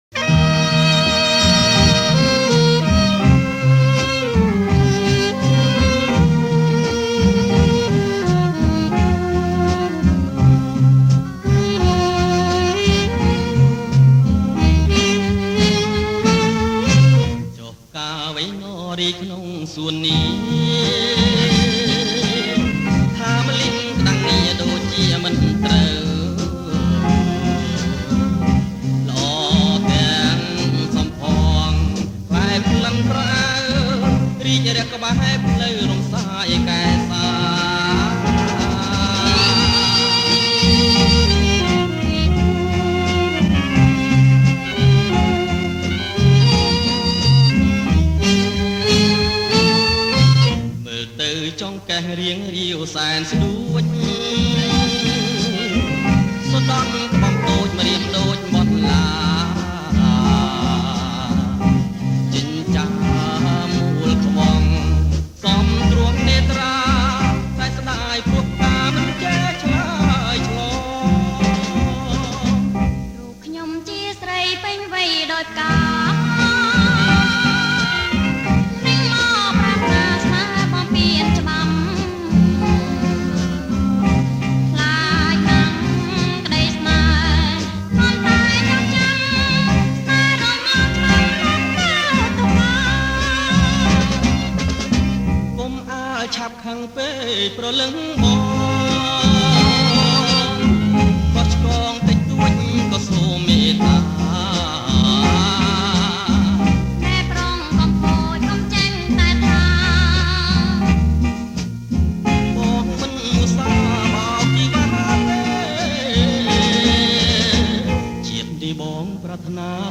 ប្រគំជាចង្វាក់  Bolero 3Temps